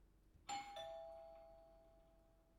doorbell
ding doorbell house sound effect free sound royalty free Sound Effects